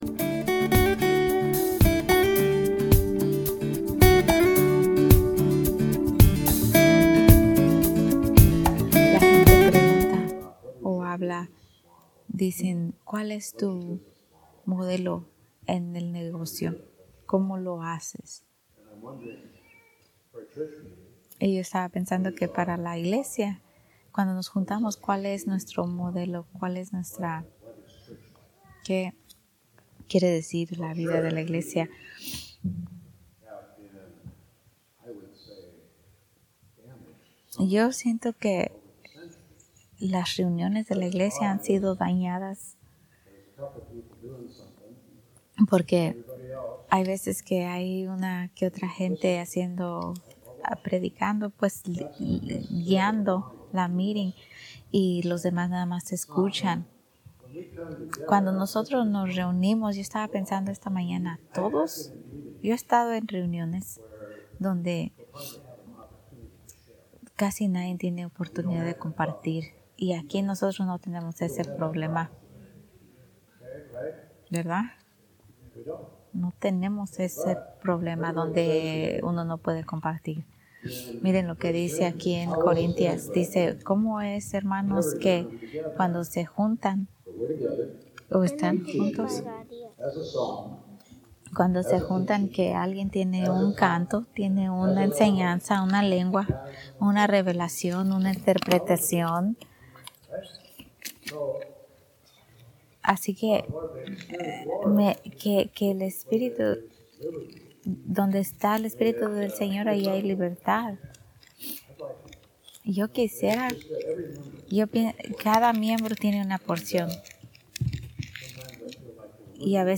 Sermones en Español